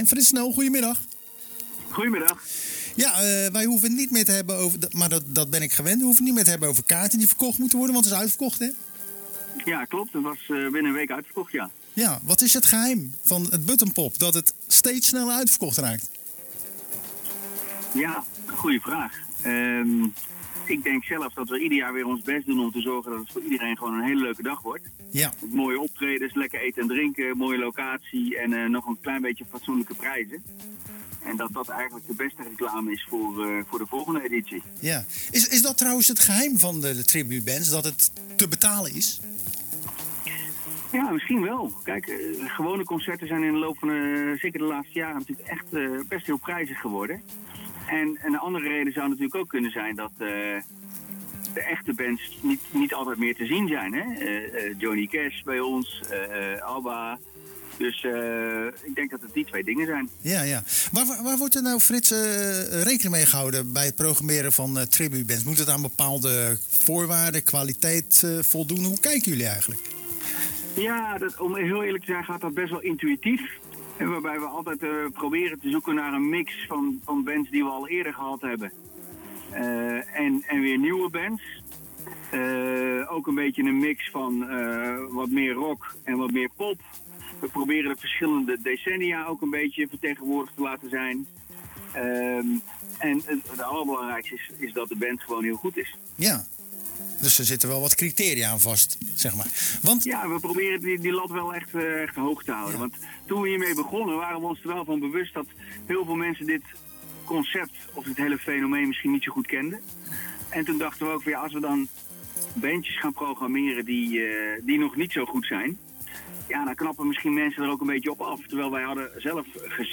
Ieder jaar bellen we tijdens het programma Zwaardvis met de organisatie van het tributefestival 'Buttonpop'.